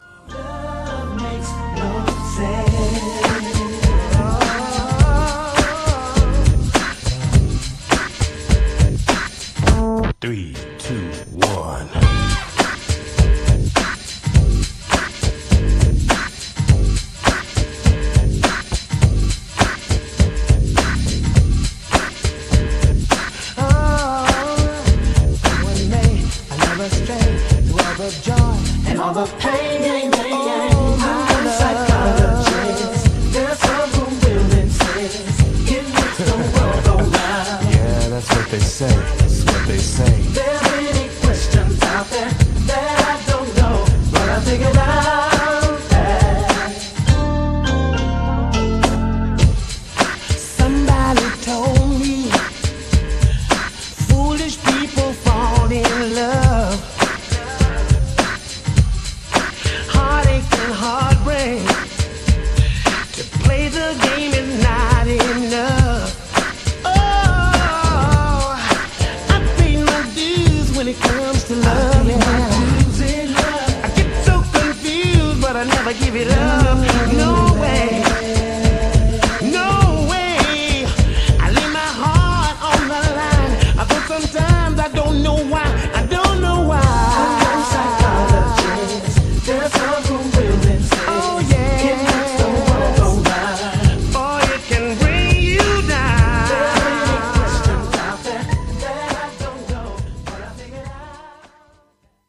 GENRE House
BPM 121〜125BPM
アップリフティング # ゴスペル # ブラコン